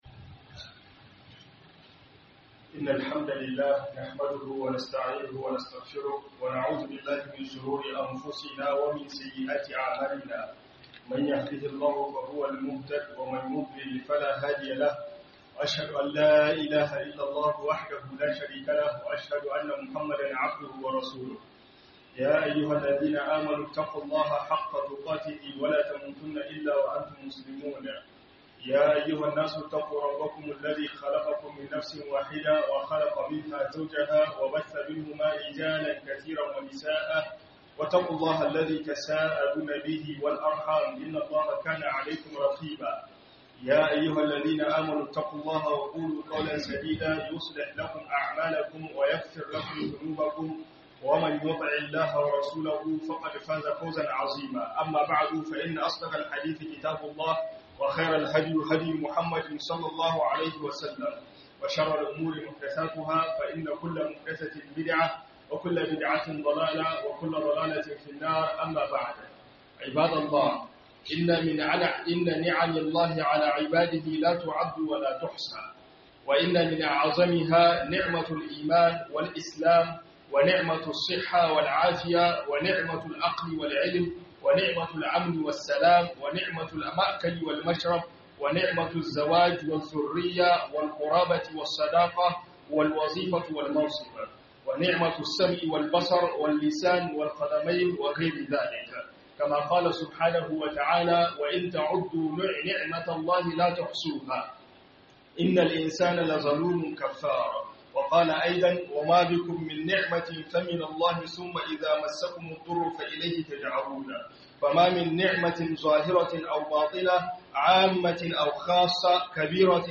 Ni'imomin Uban giji - HUDUBA